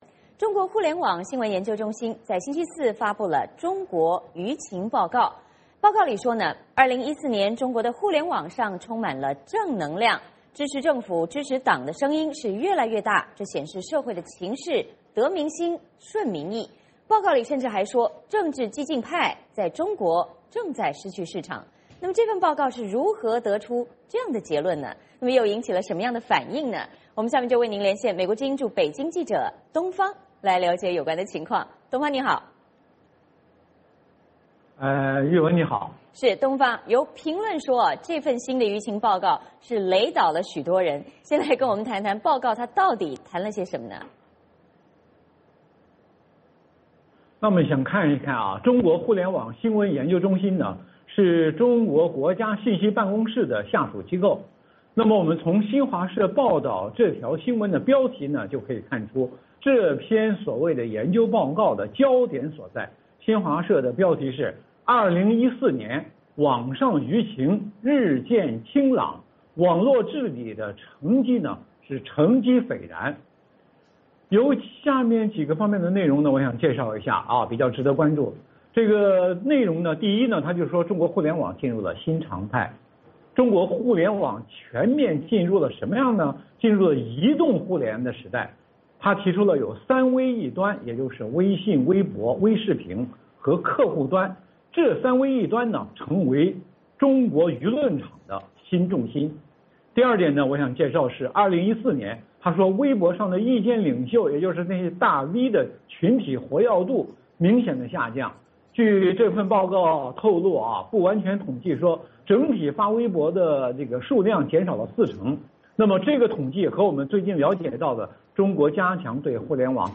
VOA连线：2014中国舆情报告引发热议